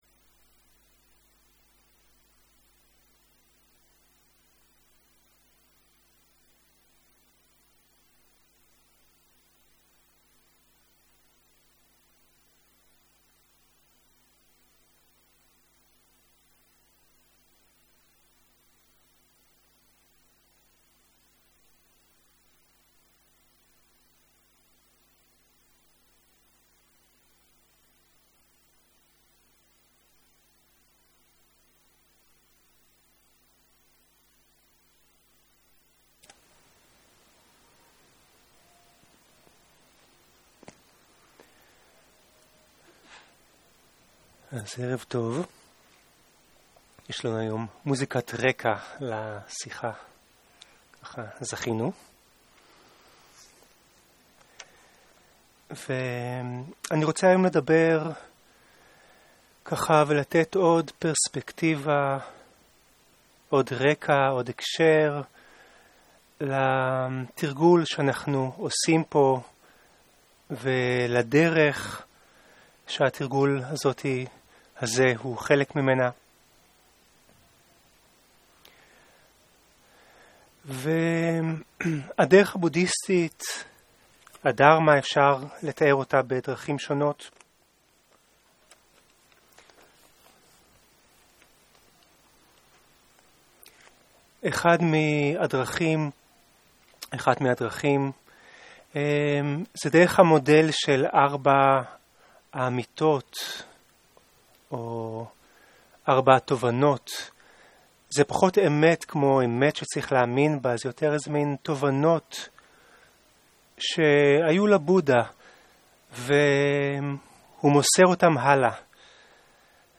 שיחת דהרמה